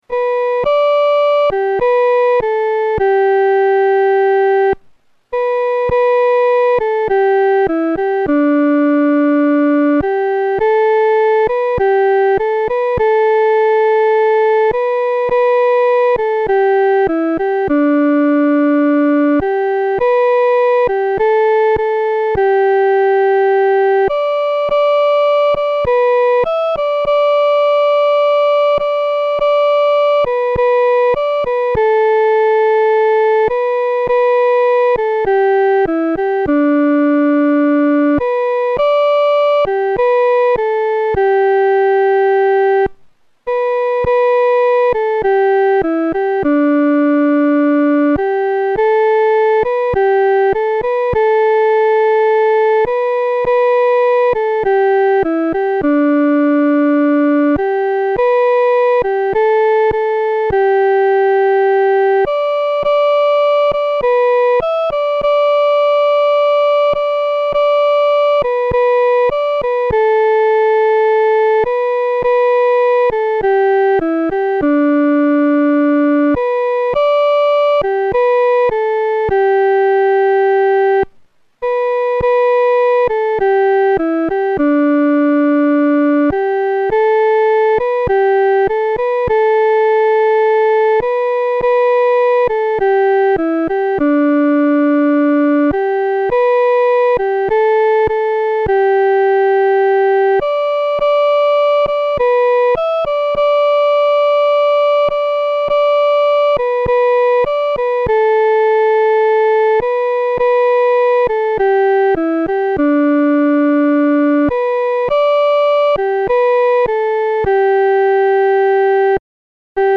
伴奏
女高
诗班在二次创作这首诗歌时，要清楚这首诗歌音乐表情是亲切、温存地。